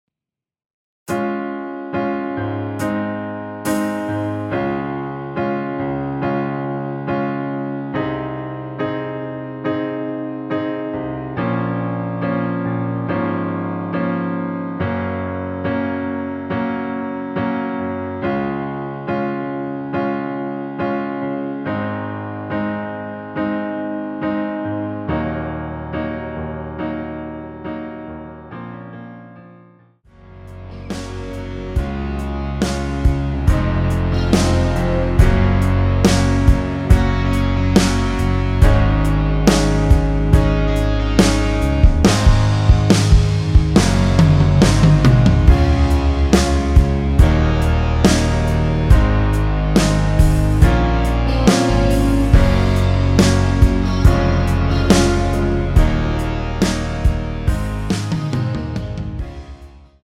노래하기 편하게 전주 1마디 만들어 놓았습니다.(미리듣기 확인)
원키에서(-1)내린 (1절+후렴)으로 진행되는 MR입니다.
Db
앞부분30초, 뒷부분30초씩 편집해서 올려 드리고 있습니다.
중간에 음이 끈어지고 다시 나오는 이유는